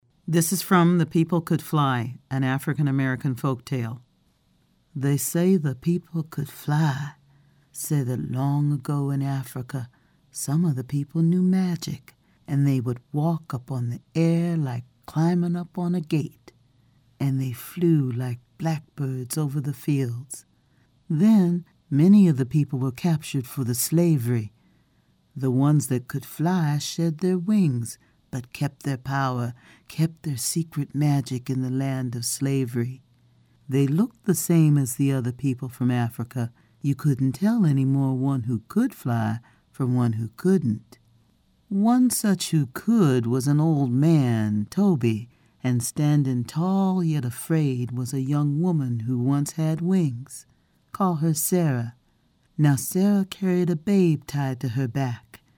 Narrator voice - intelligent, warm, conversational; broad emotional range; large repertoire of characters
Sprechprobe: Werbung (Muttersprache):